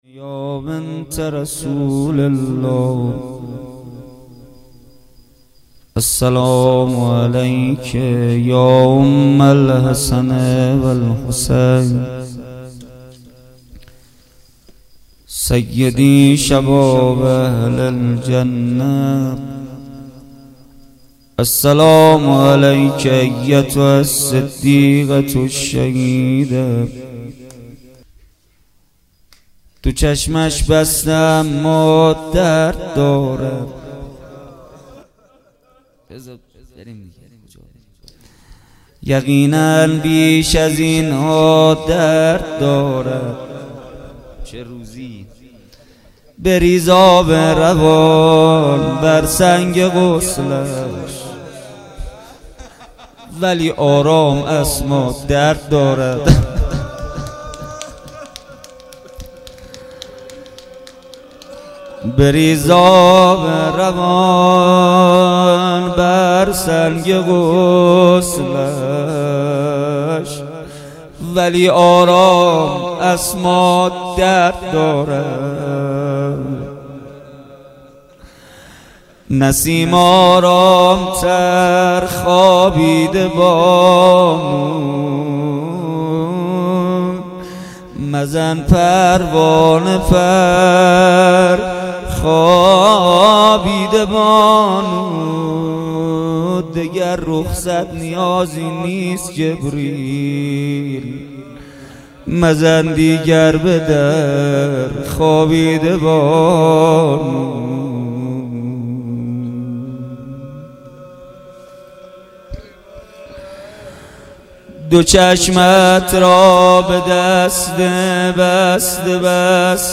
بخش اول - مناجات بخش دوم - روضه